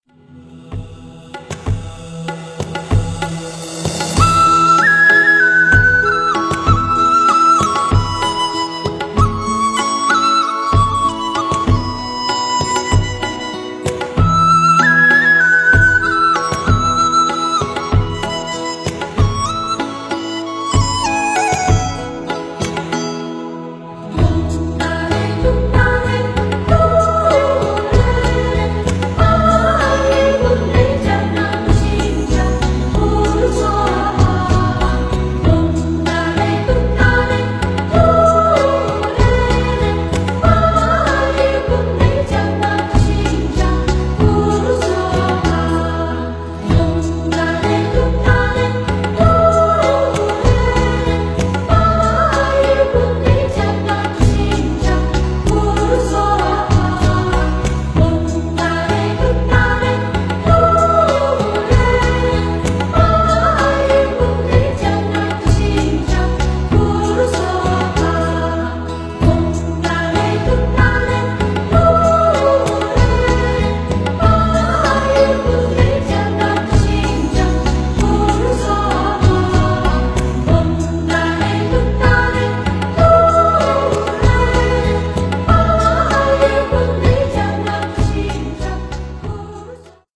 佛音 凡歌 佛教音乐 返回列表 上一篇： 坐上火车去拉萨(古筝